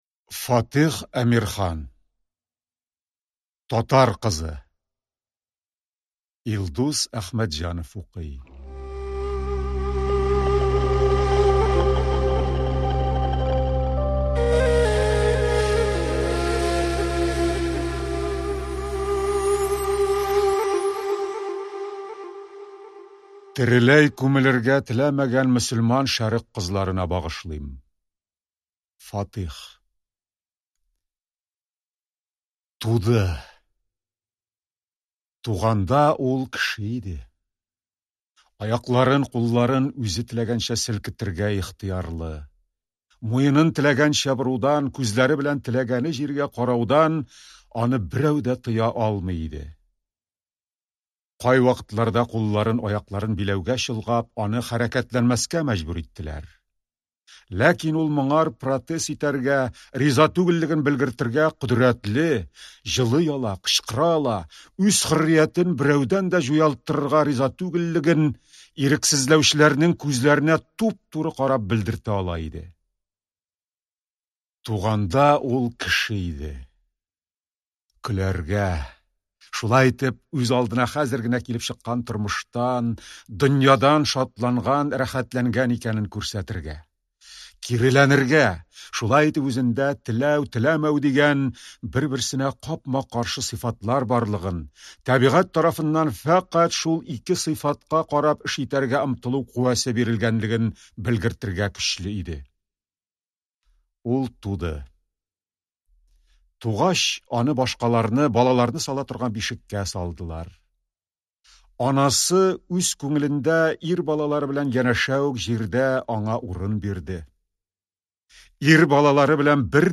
Аудиокнига Татар кызы | Библиотека аудиокниг